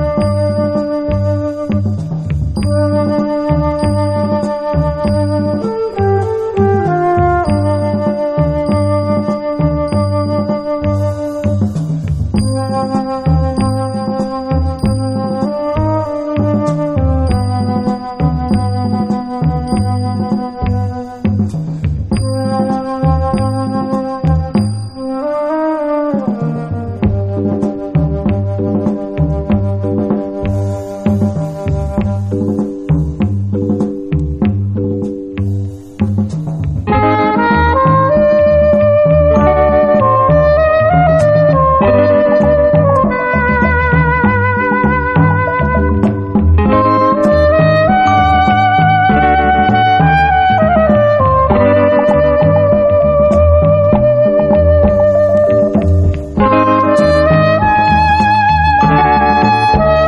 ニュー・オリンズR&Bクラシックを網羅したグレイト・コンピ！
イントロにドラム・ブレイク入り